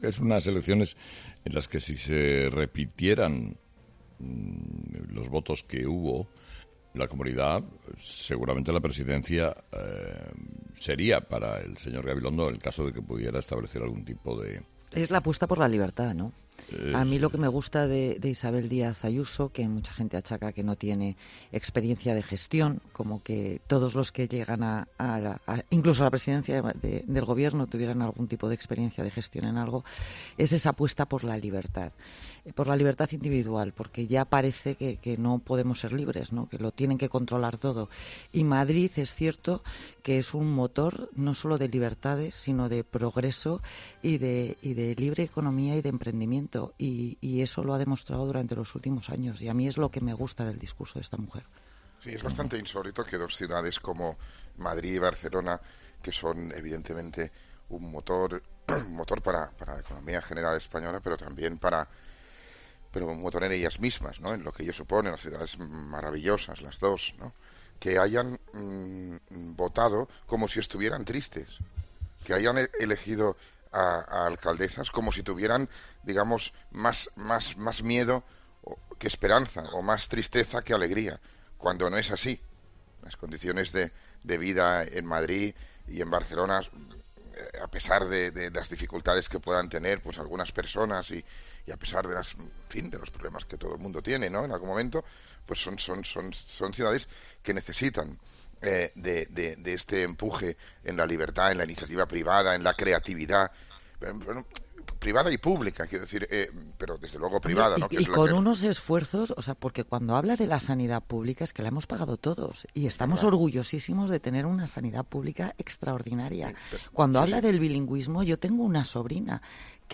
El rifirrafe de los tertulianos de Herrera con él de por medio: “Tómate un café”, “¡Y tú una tila!”